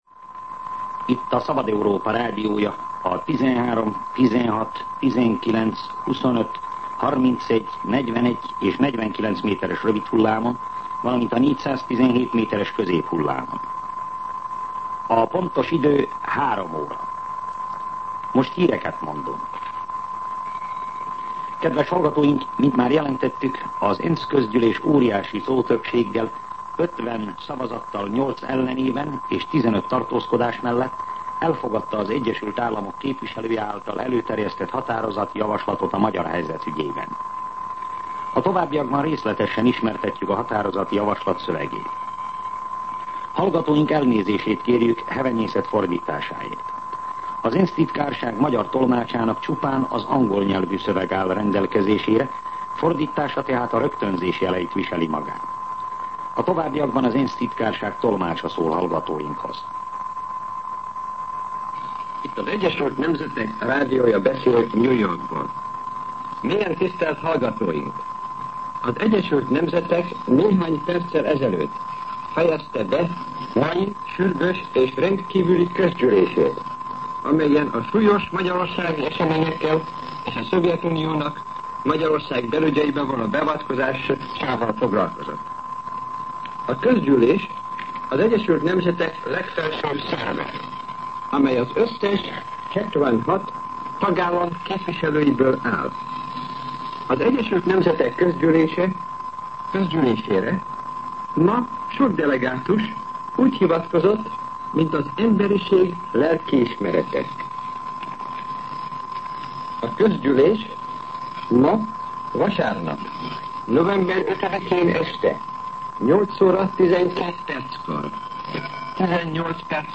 03:00 óra. Hírszolgálat